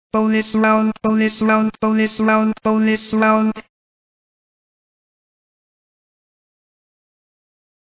Video Game Sound FX Robot Voice , "Bonus Round"
Video game sound fx. Robot voice says, "Bonus Round."